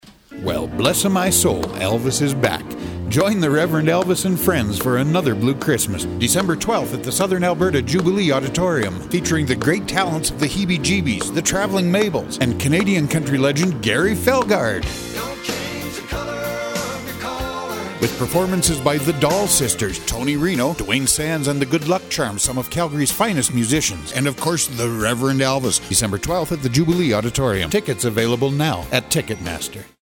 30-SEC-RADIO-SPOT.mp3